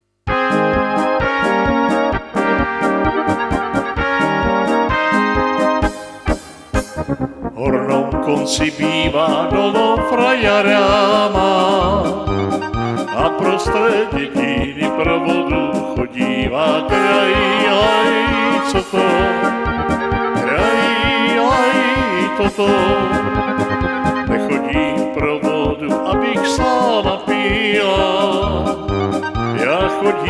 Rubrika: Národní, lidové, dechovka
- směs - polka